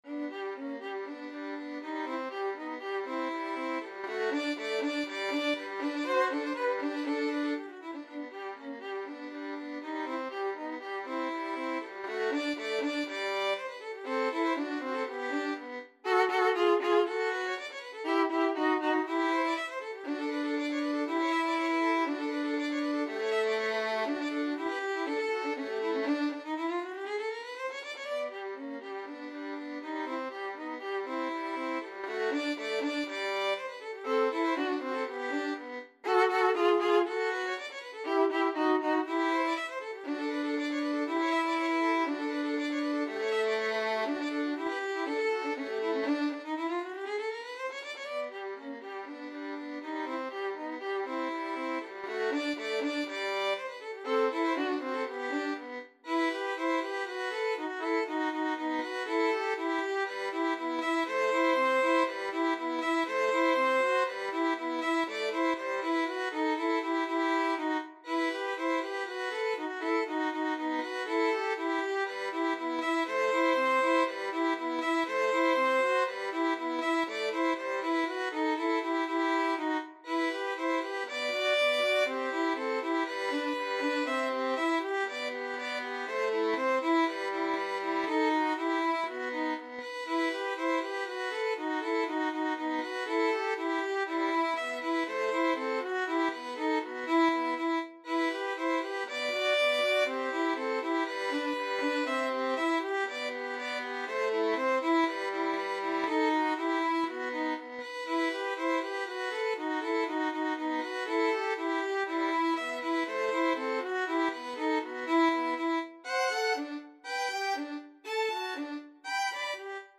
2/4 (View more 2/4 Music)
G major (Sounding Pitch) (View more G major Music for Violin Duet )
Rondo - Allegro (View more music marked Allegro)
Violin Duet  (View more Intermediate Violin Duet Music)
Classical (View more Classical Violin Duet Music)